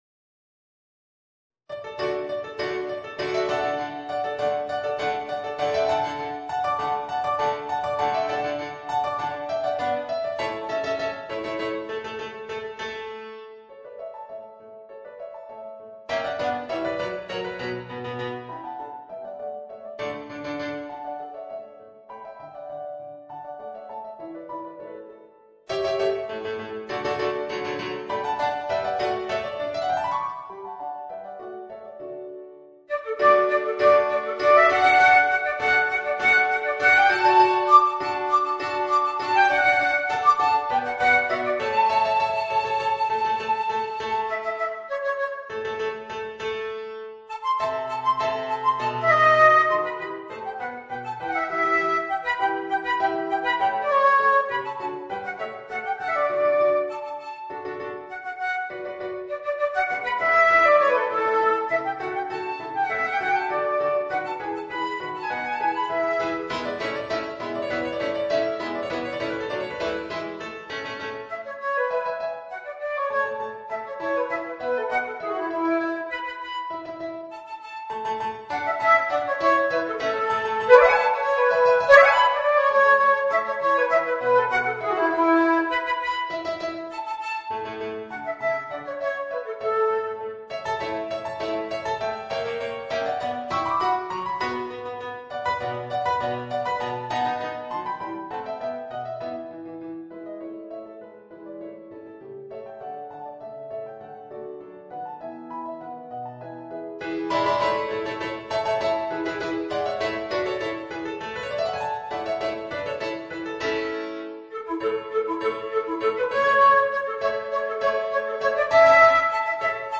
(フルート＋ピアノ)